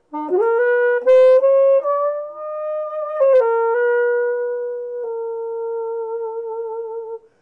Vorgelesen von echten Menschen – nicht von Computern.